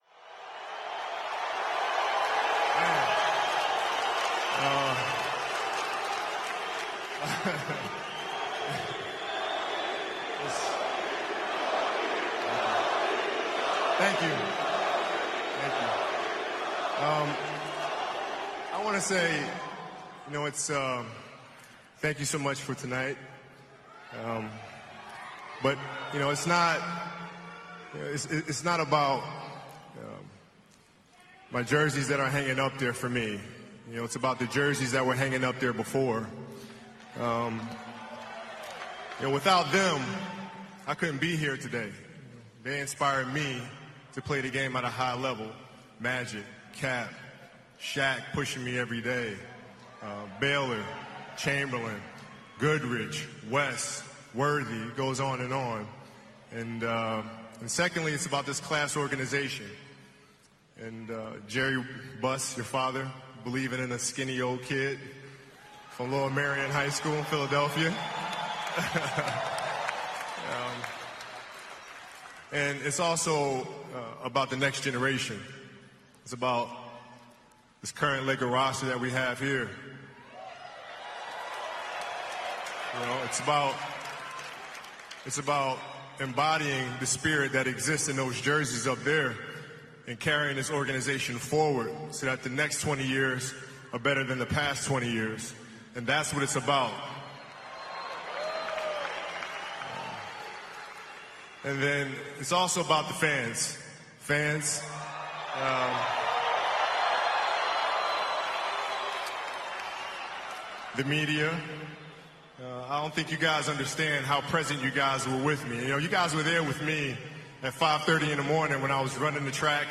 Kobe Bryant – speech at ceremony retiring #6 and #24 – December 18, 2017 –
As way of a tribute and memory to the man and the legend, here is the short speech Kobe Bryant gave during the ceremony retiring Lakers Jersey #6 and #24 at the Staples Center on December 18, 2017.